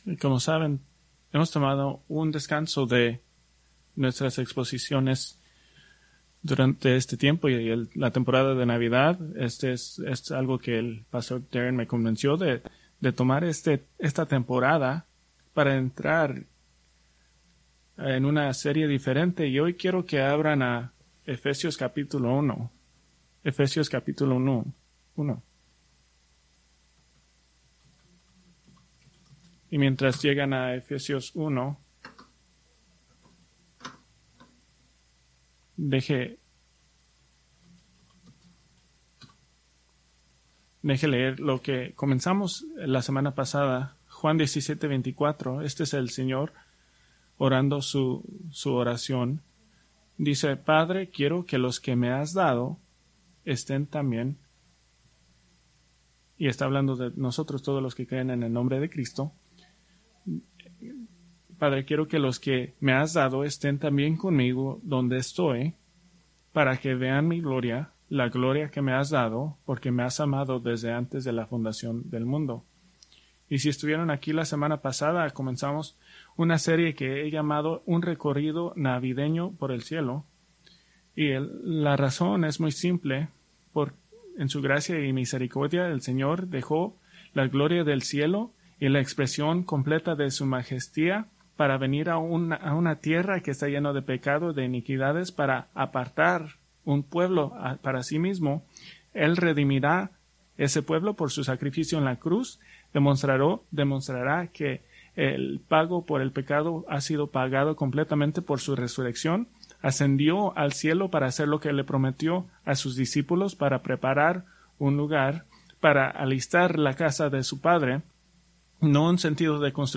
Preached December 15, 2024 from Escrituras seleccionadas